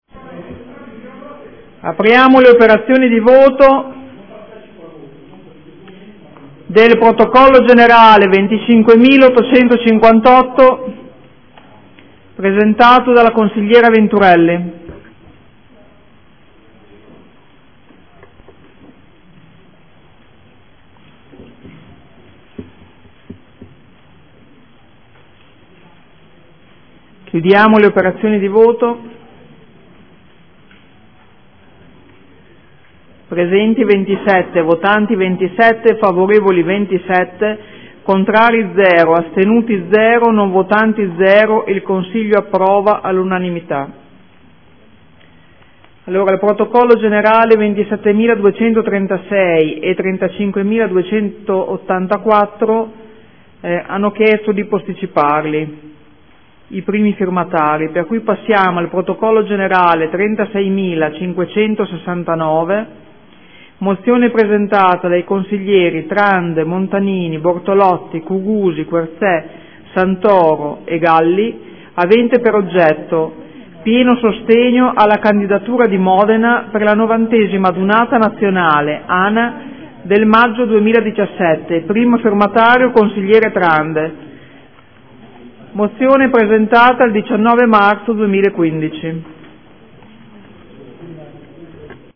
Presidentessa